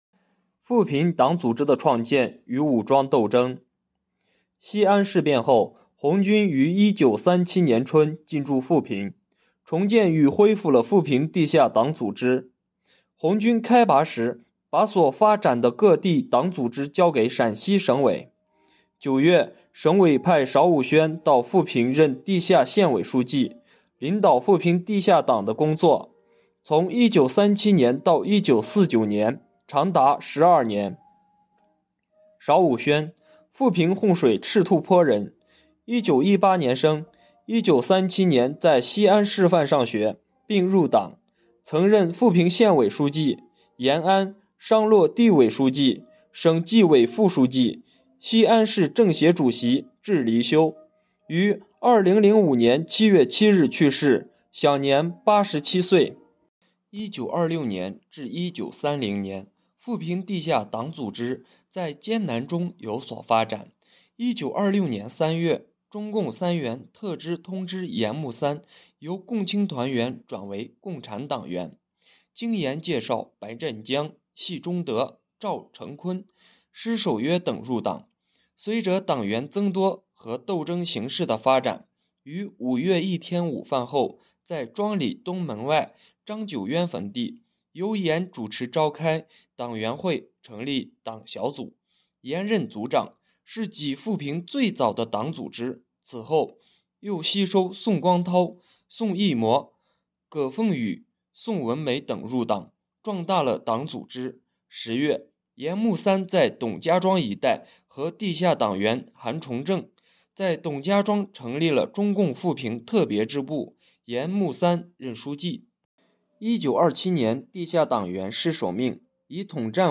【红色档案诵读展播】富平党组织的创建与武装斗争